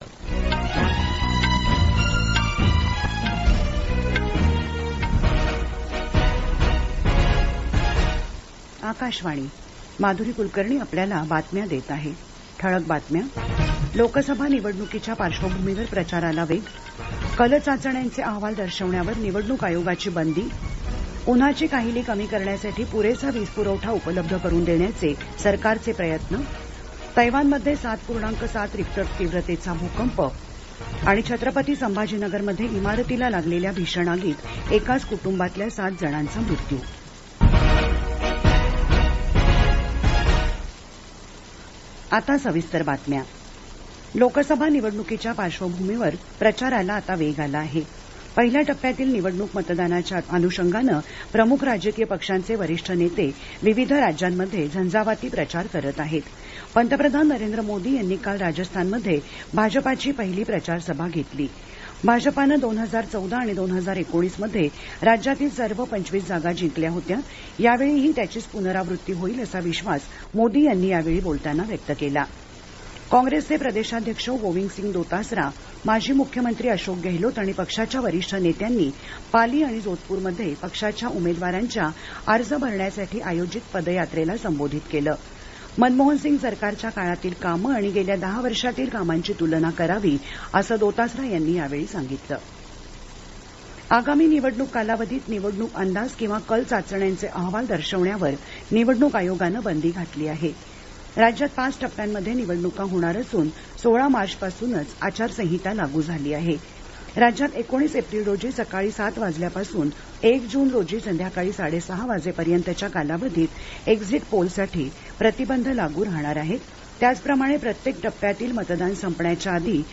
Transcript summary Play Audio Evening News